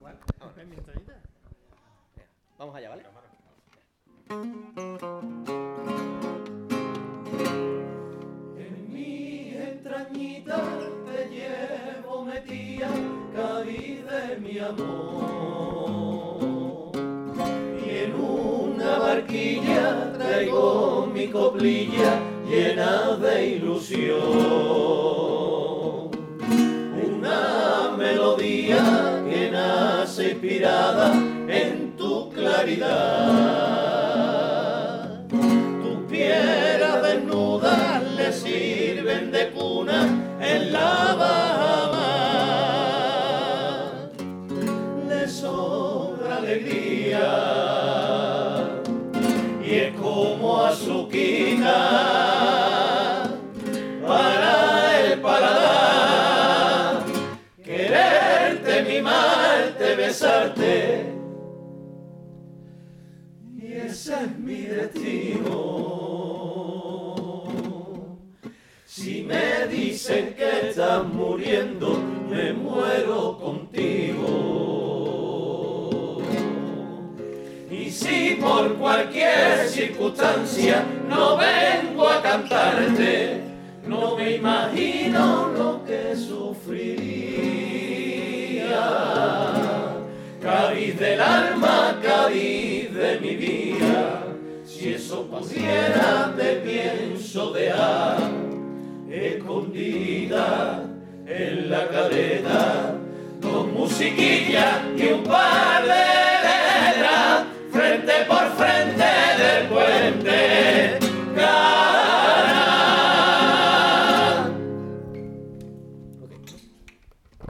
guitarra/voz
Guitarra y voz de la comparsa Los Invasores formato MP3 audio(2,66 MB)